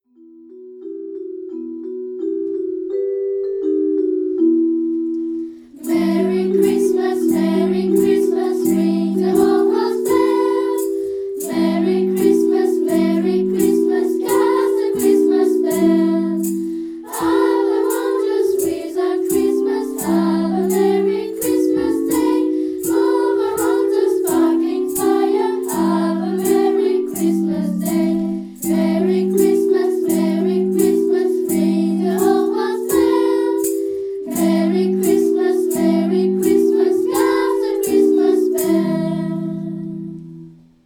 La chorale du collège vous souhaite de belles fêtes de fin d'année